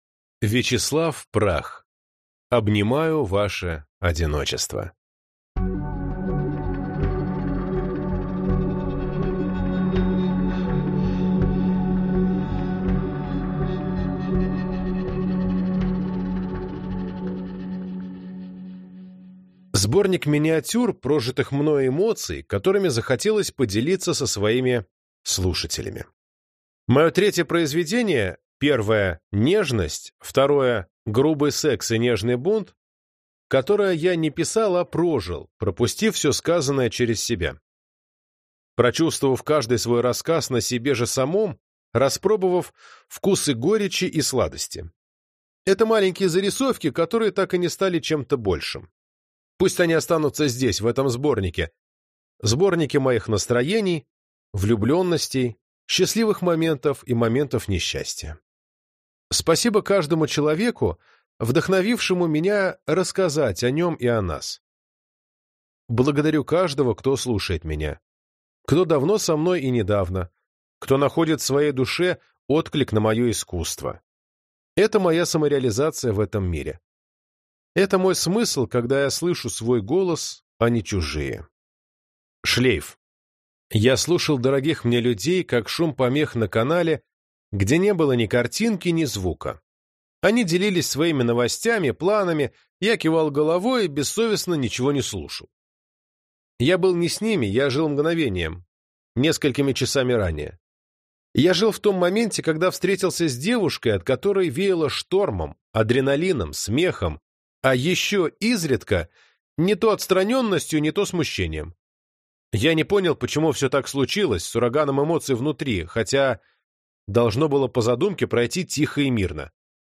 Аудиокнига Обнимаю ваше одиночество | Библиотека аудиокниг